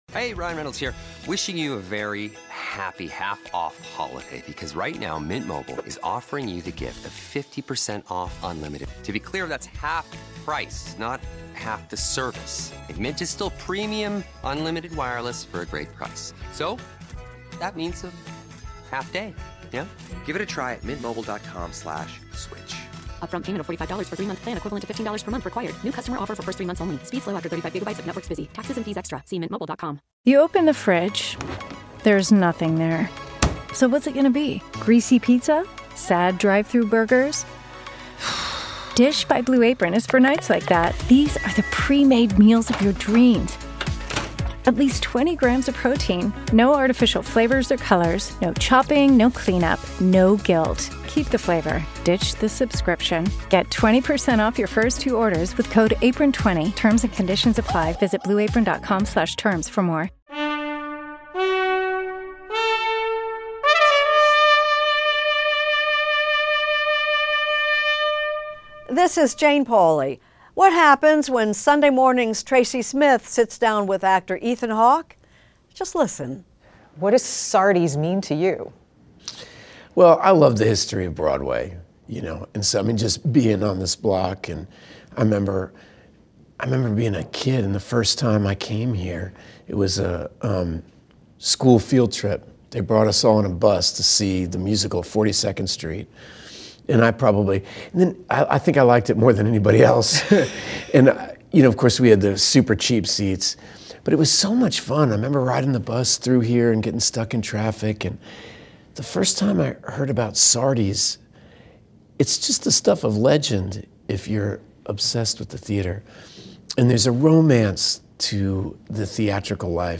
Extended Interview: Ethan Hawke